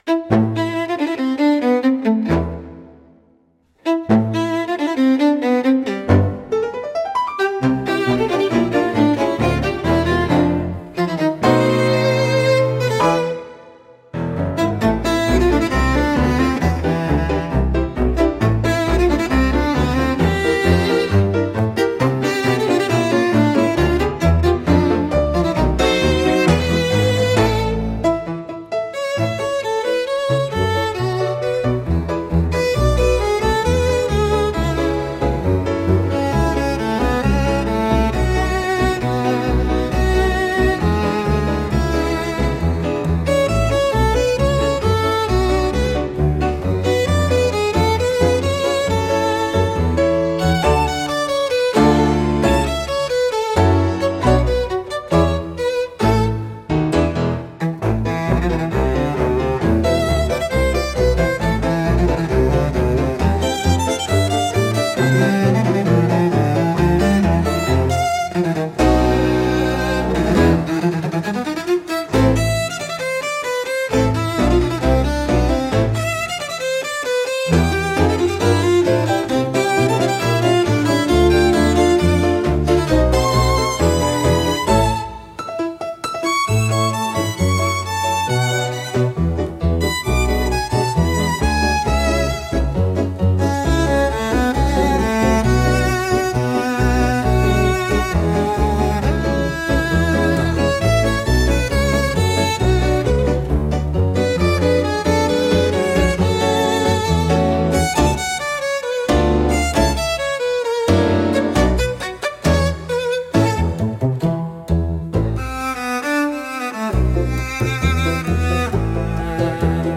música e arranjo: IA) Instrumental TICO-TICO NO FUBÁ